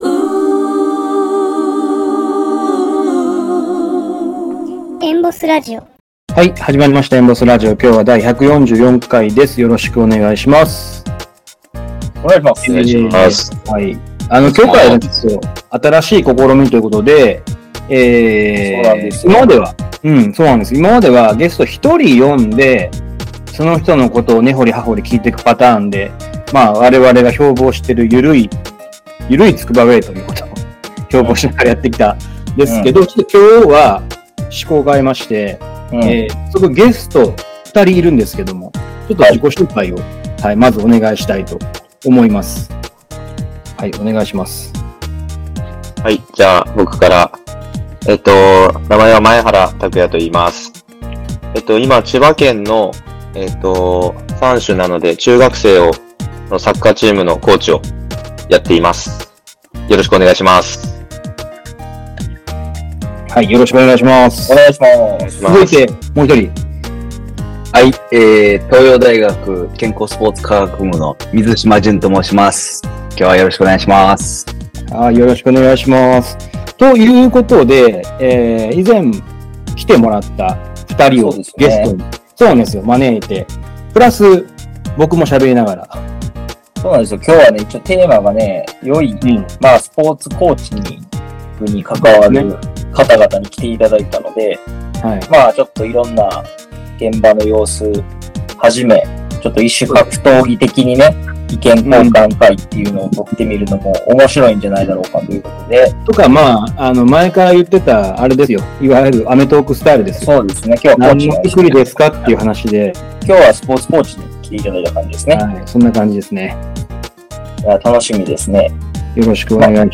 3人で喋っていたら，「どこのシンポジウム？」って感じになりました